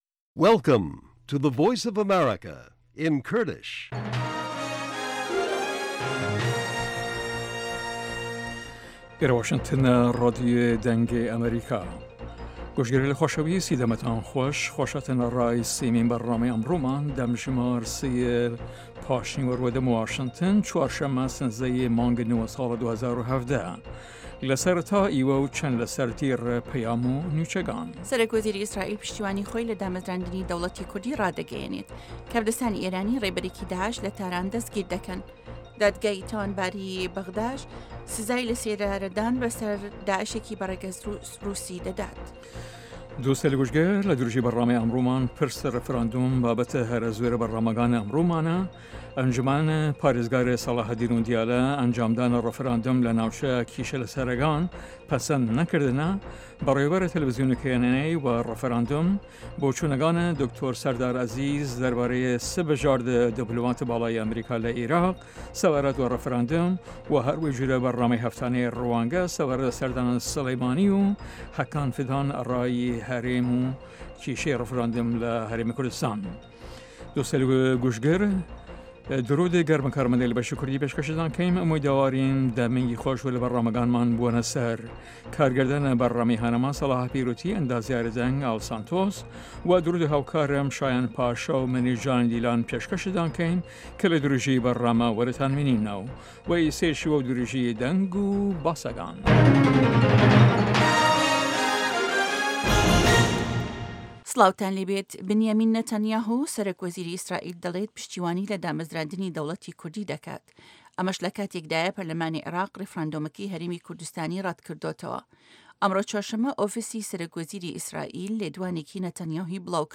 سه‌ره‌تای به‌رنامه‌ و چه‌ند هه‌واڵێـک